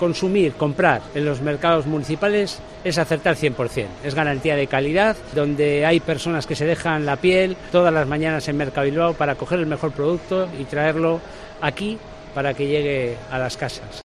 Alfonso Gil, Teniente de Alcalde de Bilbao